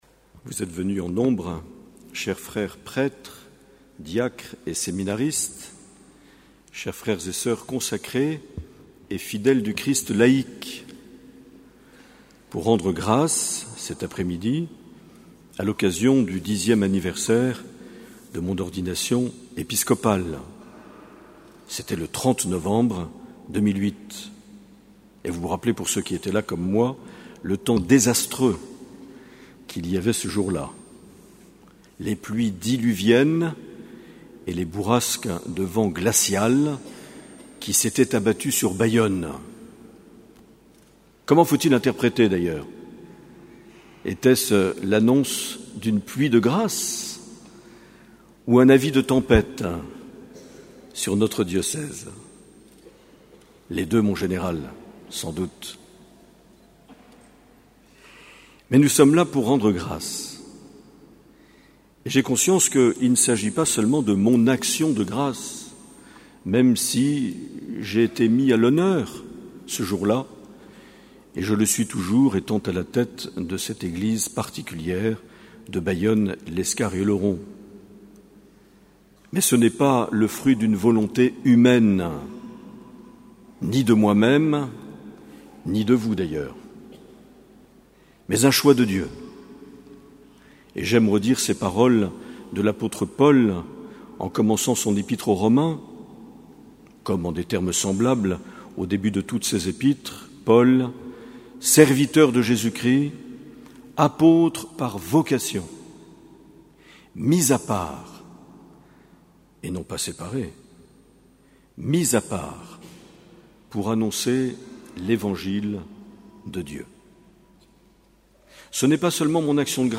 2 décembre 2018 - Cathédrale de Bayonne - 10 ans d’épiscopat
Une émission présentée par Monseigneur Marc Aillet